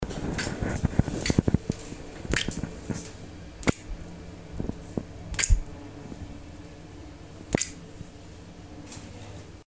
Diferentes goteos de agua
Me gusta Descripción Grabación sonora del sonido de diferentes goteos de agua. Sonidos cotidianos